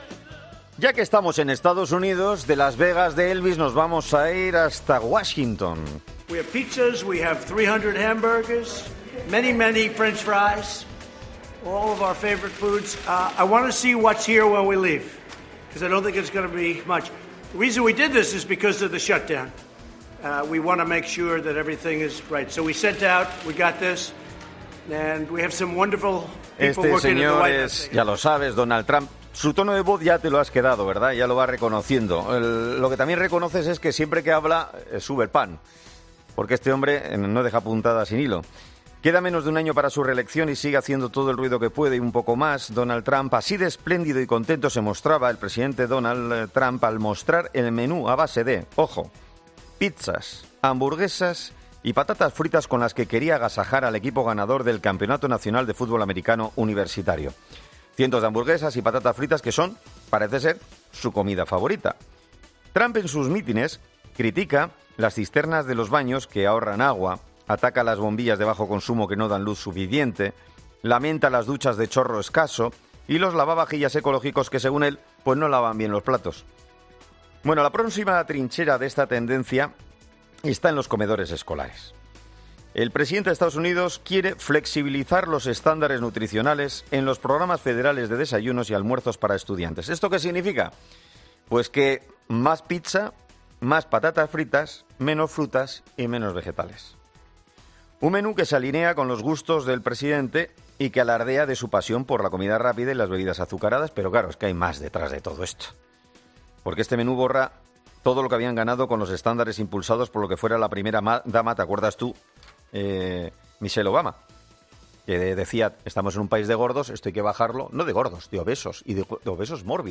Para conocer los riesgos que entraña la dieta propuesta por Trump, este lunes ha sido entrevistado en 'Herrera en COPE'